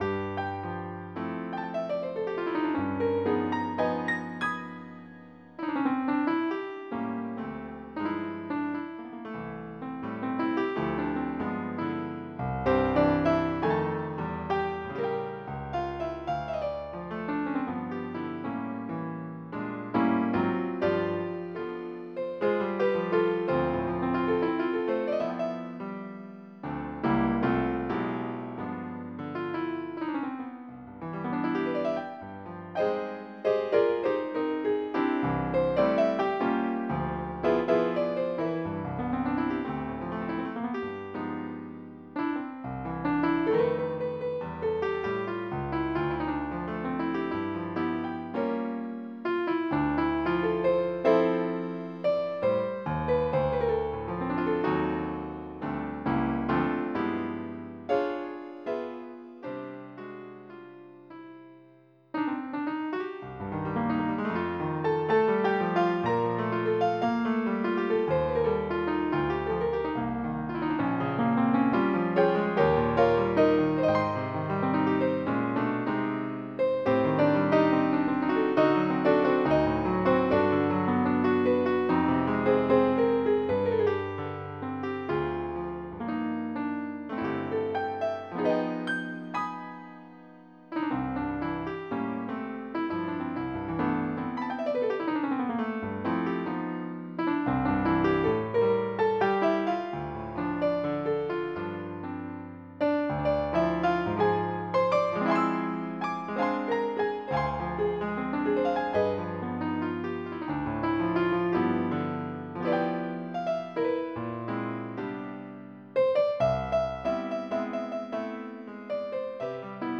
Jazz
MIDI Music File